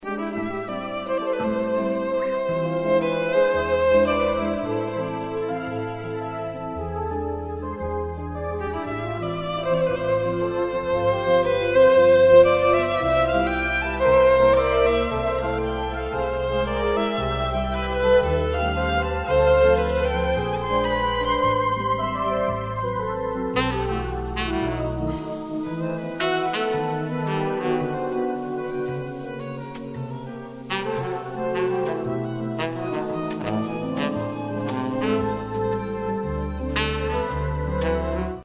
klávesové nástr, bicí aut., klavír
saxofony, klarinet, perkuse
bicí
kytary, perkuse